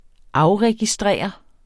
Udtale [ ˈɑwʁεgiˌsdʁεˀʌ ]